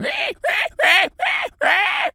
pgs/Assets/Audio/Animal_Impersonations/pig_scream_01.wav at master
pig_scream_01.wav